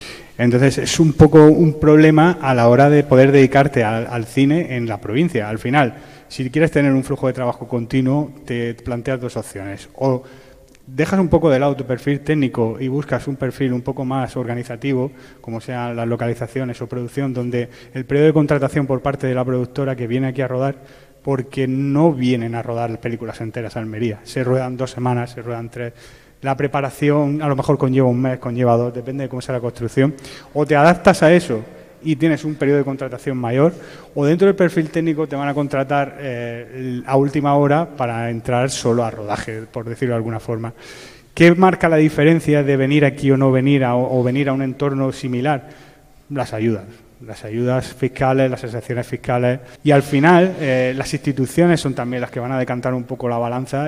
En el debate desarrollado en el salón de actos del Museo del Realismo Contemporáneo (MuReC) se han abordado temas como el impulso al talento joven y la necesidad de crear itinerarios formativos completos para consolidar la industria audiovisual como uno de los motores culturales y económicos de la provincia.